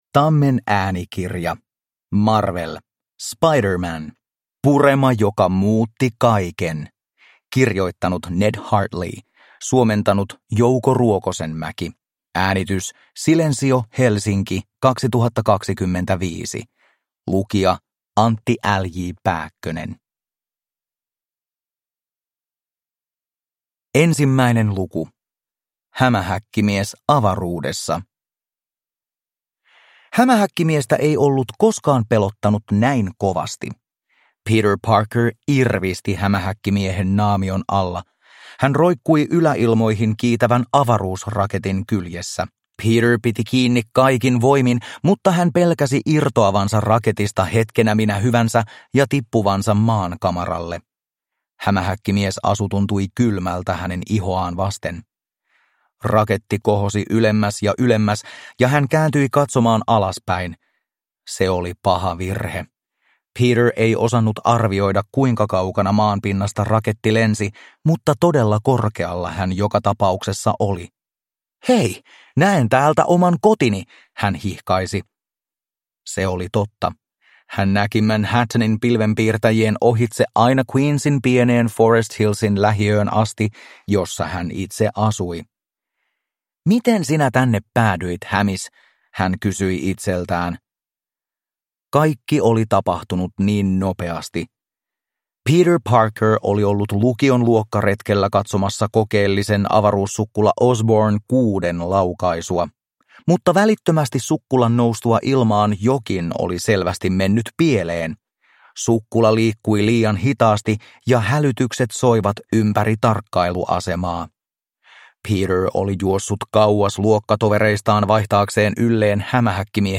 Marvel. Spider-Man. Purema, joka muutti kaiken – Ljudbok